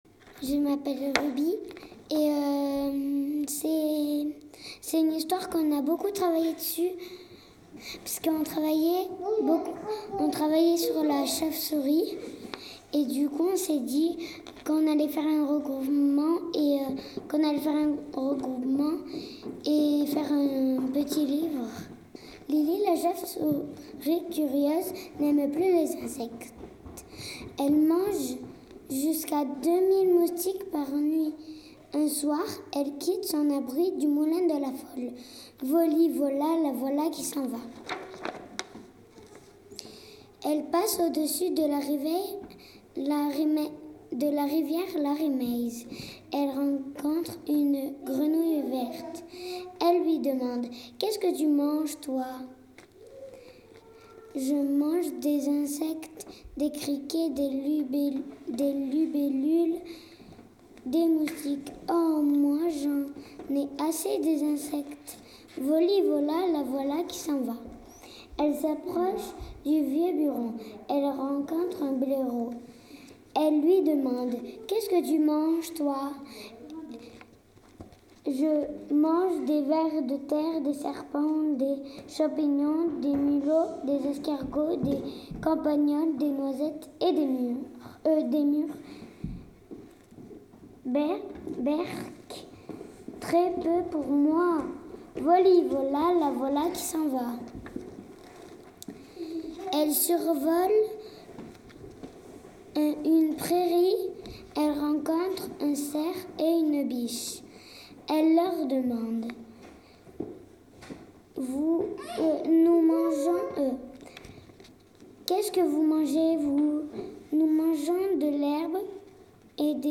Les sonoportraits
comptine en français